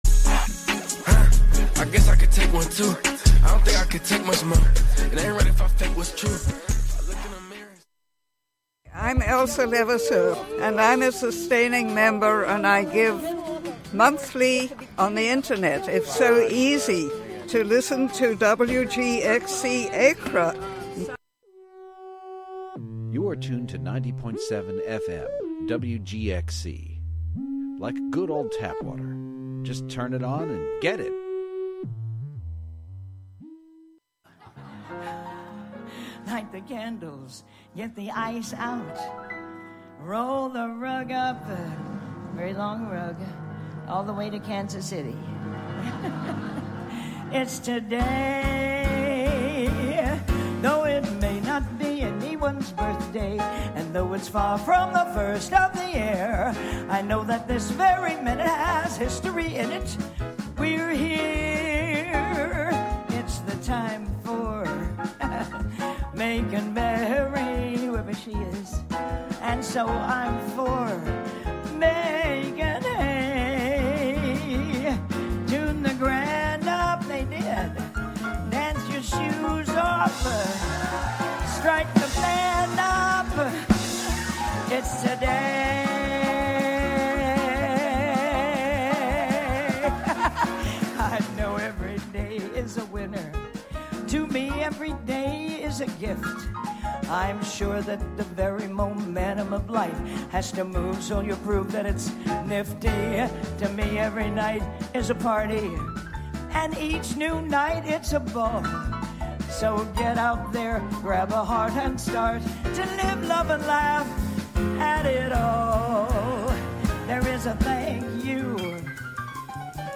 Discovered by St eve Allen and beloved by Johnny Carson who featured her on The Tonight Show no less than 76 times, the cabaret singer returns to Hudson Hall for one night only. Tune into the “WGXC Afternoon Show” for local news, interviews with community leaders and personalities, reports on cultural issues, a rundown of public meetings and local and regional events, weather updates, and more about and for the community, made by volunteers in the community.